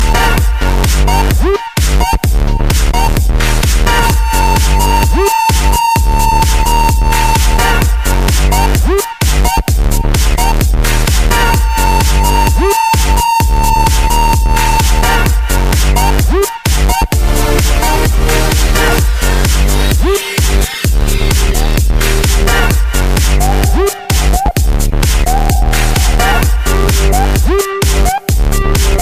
Dance - Electro